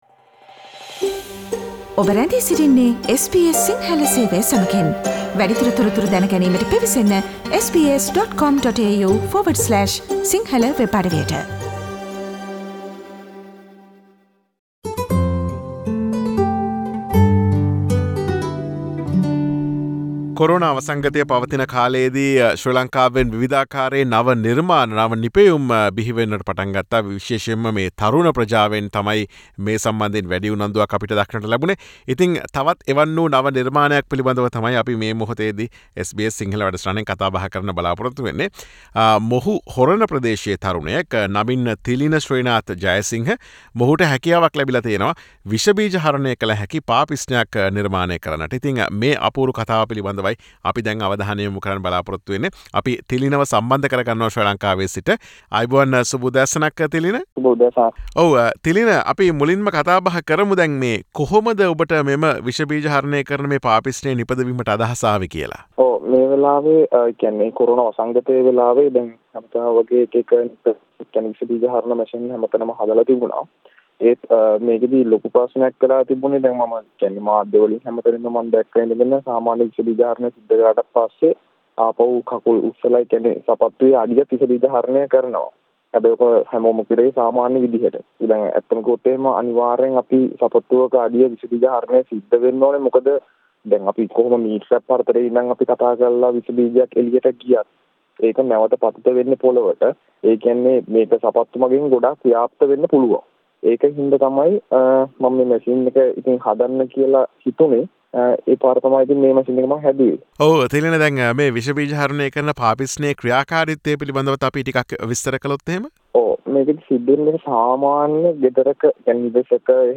He speaks to SBS Sinhalese about his achievement.